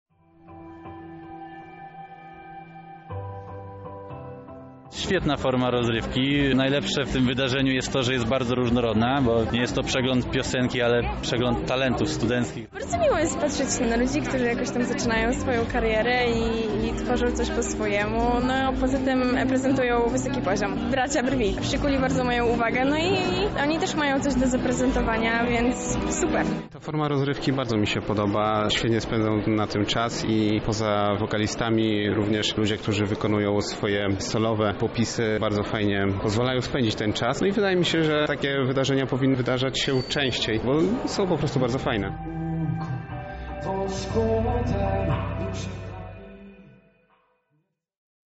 Finał miał miejsce w Sali Operowej Centrum Spotkania Kultur.